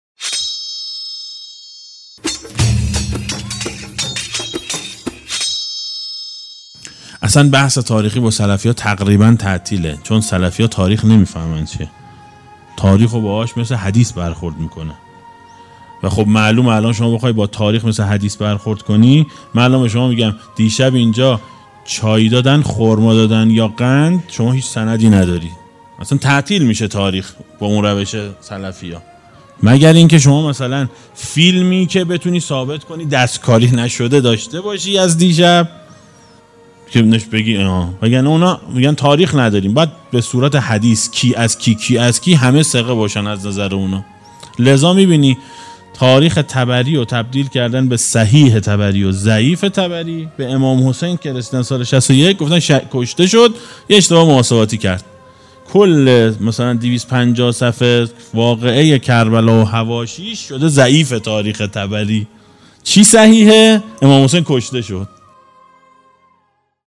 دسته: سخنرانی ها , یادداشت صوتی قبلی قبلی امیرالمؤمنین علیه السلام از نظر عایشه بعدی کار کردن ائمه علیهم السلام بعدی